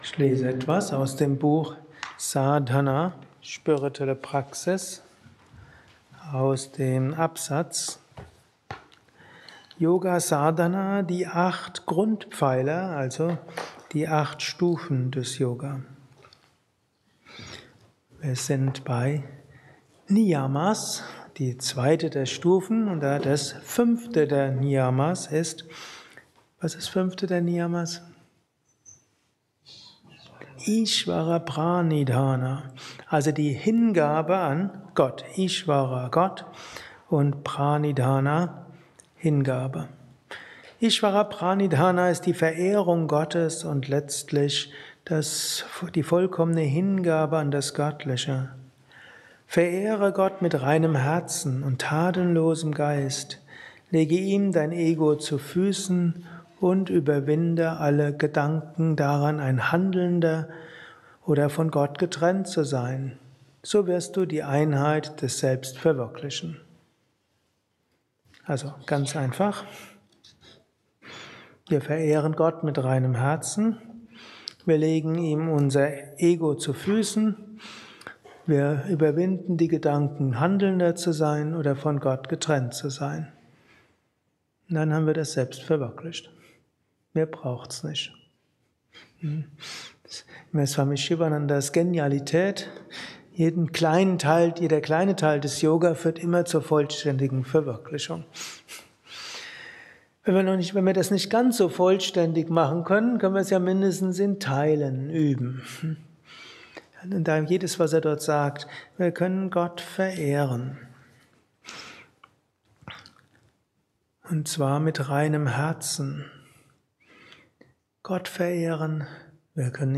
Dies ist ein kurzer Vortrag als
einer Meditation im Yoga Vidya Ashram Bad Meinberg.